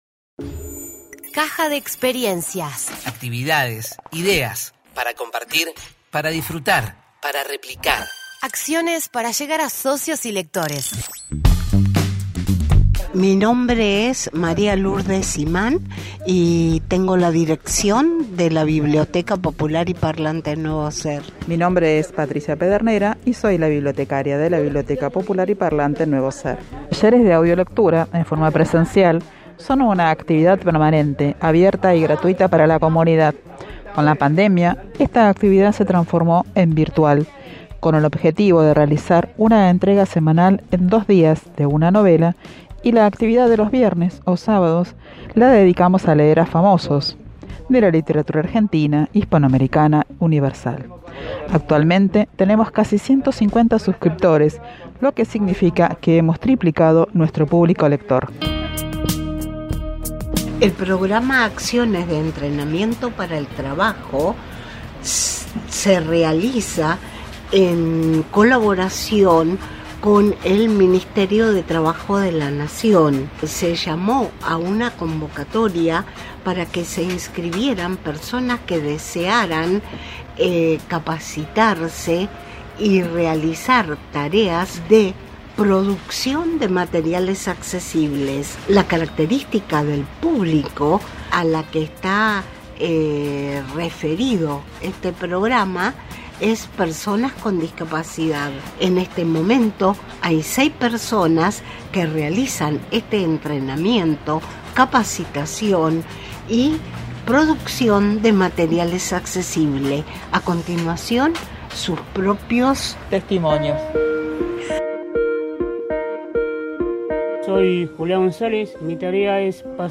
La biblioteca comparte la experiencia de los talleres de audiolectura y cómo se transformó en un espacio de capacitación de producción de Materiales Accesibles. No te pierdas el testimonio de los participantes!